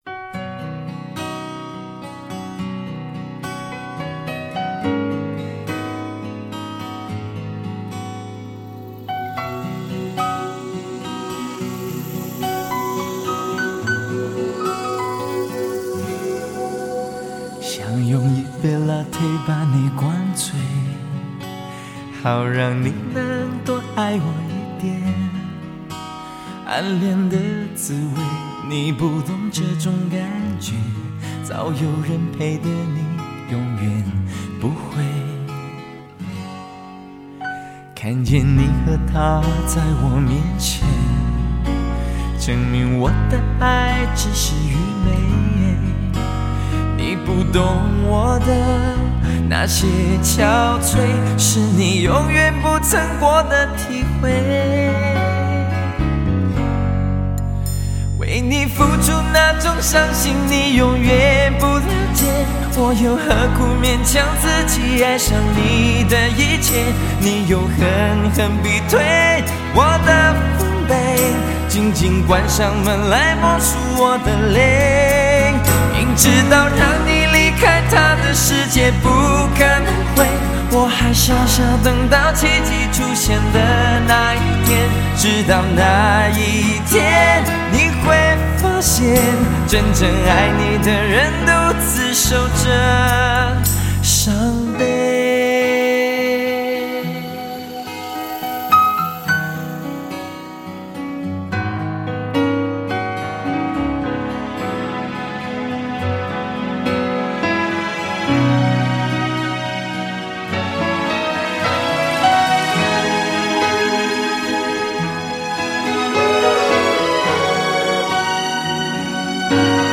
HQ高品质 TOP MUSIC
LP黑胶 精装10碟 HI-FI音质